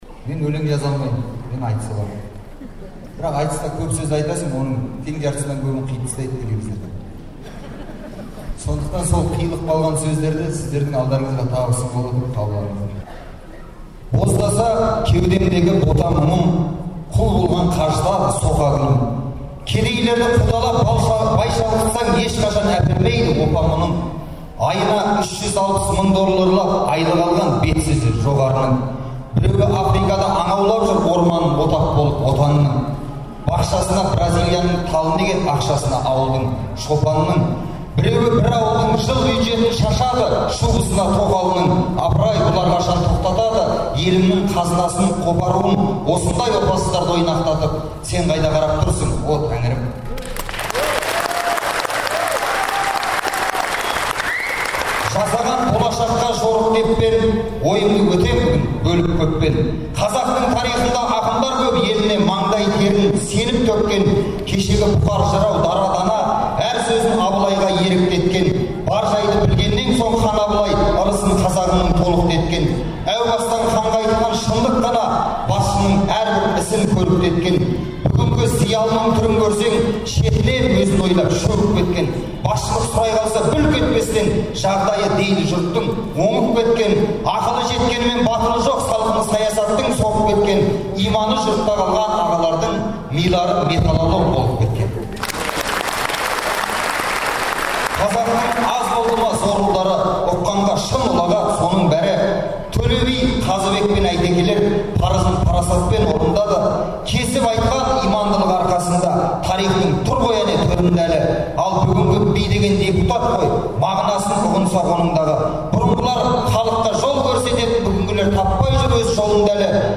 Алматыда айтыс ақыны Ринат Зайытовтың «Кездесуге кел» атты шығармашылық кеші өтті.
Ринат Зайытовтың айтысынан үзінділер